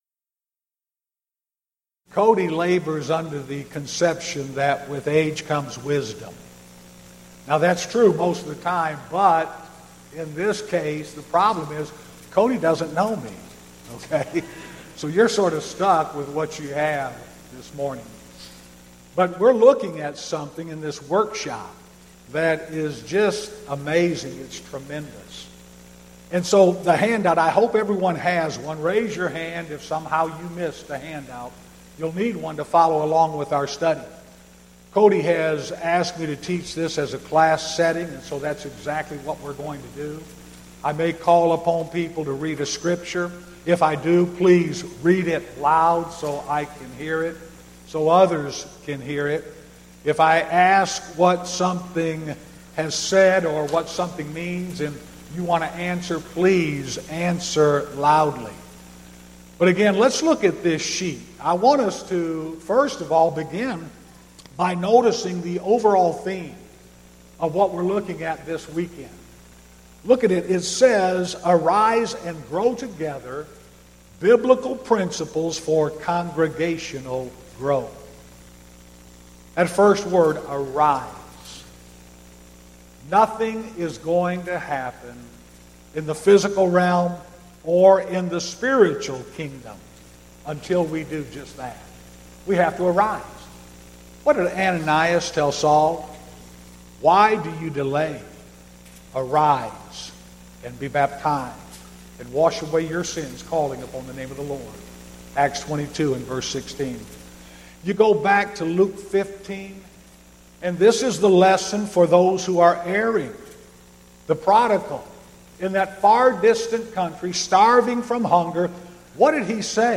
Event: 4th Annual Arise Workshop Theme/Title: Biblical Principles for Congregational Growth